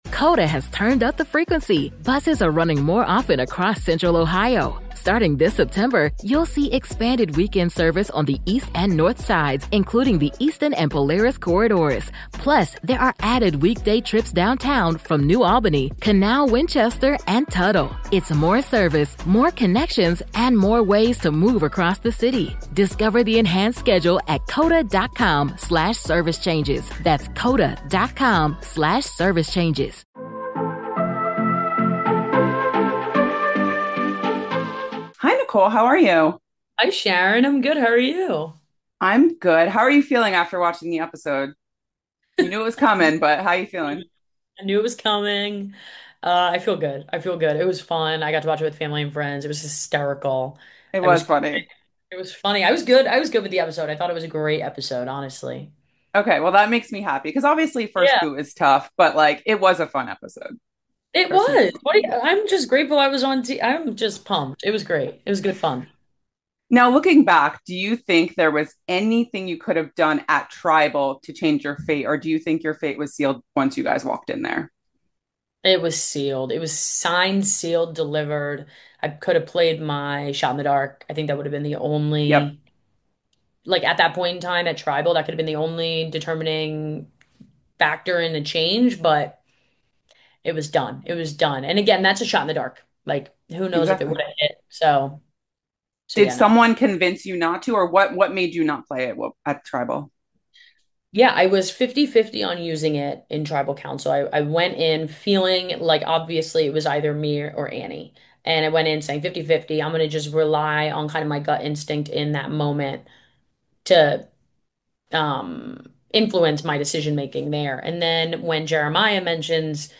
Survivor 49 Exit Interview